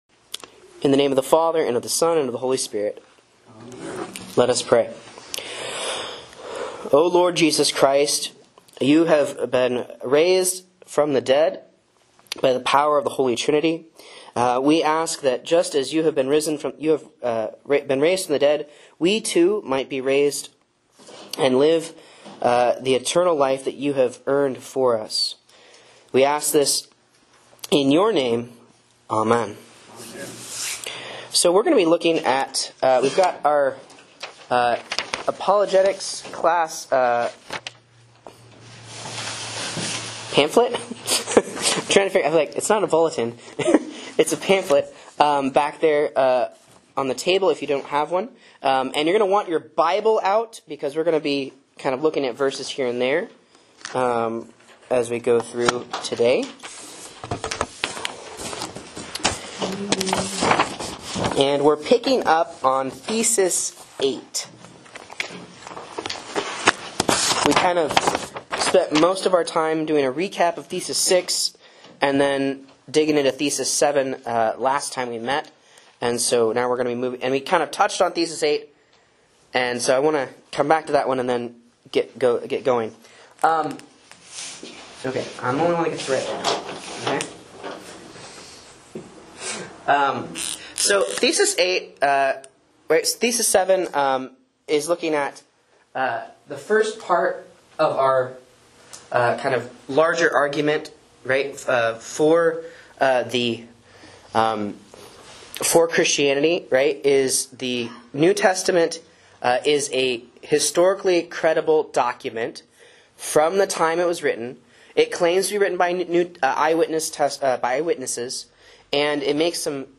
Sermons and Lessons from Faith Lutheran Church, Rogue River, OR
A Sunday School Class on Lutheran Apologetics